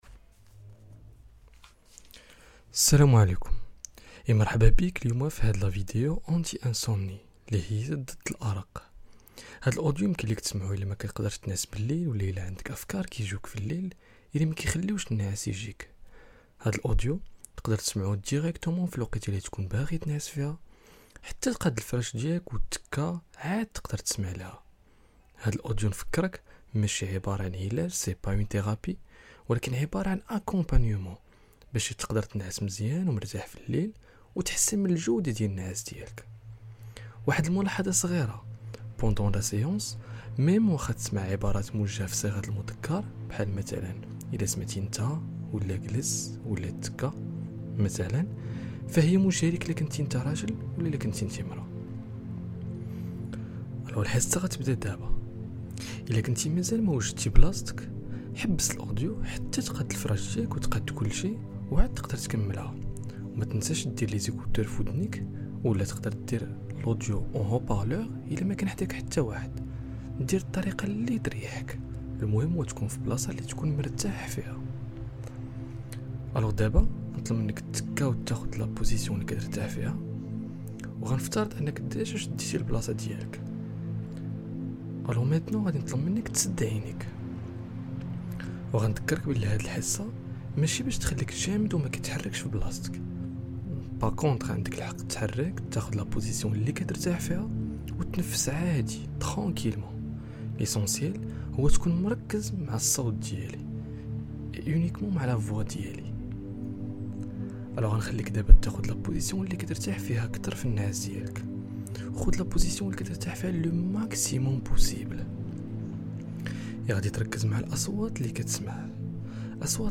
ANTI-INSOMNIE - Hypnose
ANTI-INSOMNIE.mp3